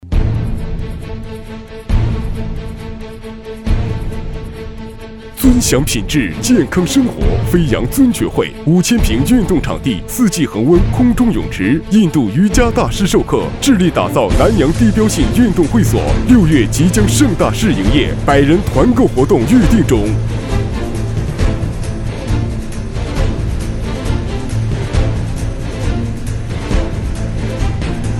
C男165号
【促销】电台浑厚有力
【促销】电台浑厚有力.mp3